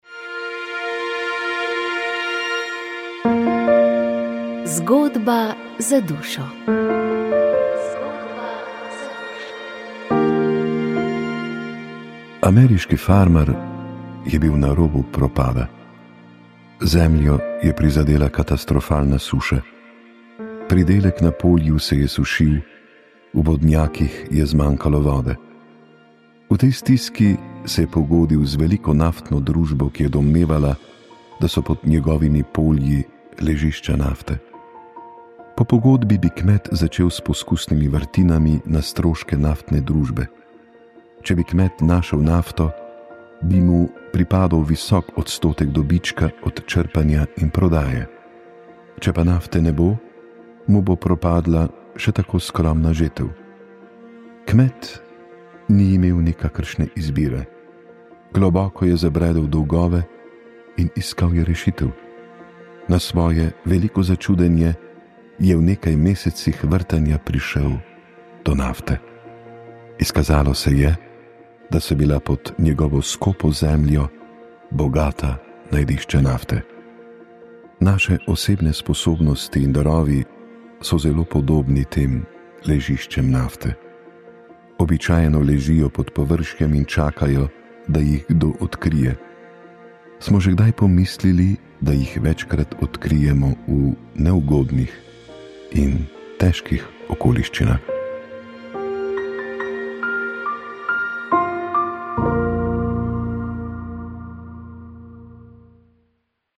Po materinskem dnevu smo v oddajo Pogovor o povabili tri ugledne in tudi nagrajene menedžerke, ki uspešno usklajujejo kariero in družinsko življenje. Kako se pri tem izogniti stresu? Kako postaviti meje?